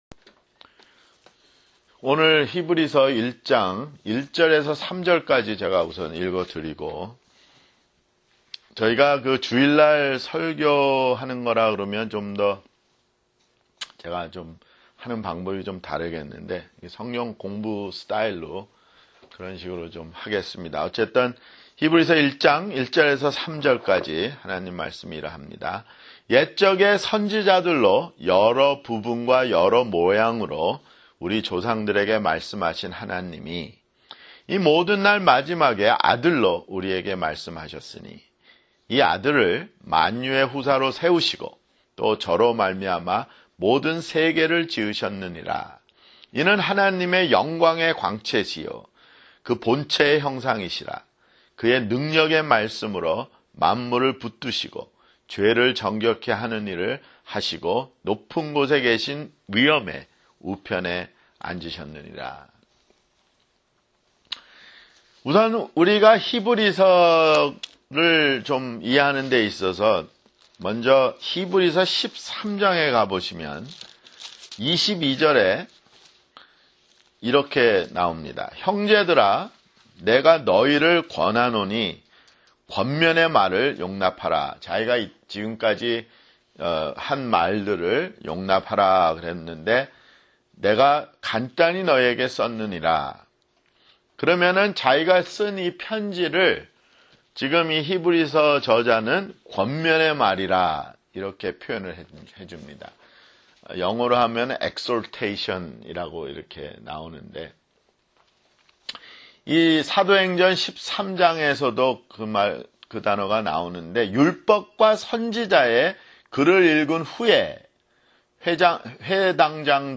[성경공부] 히브리서 (2)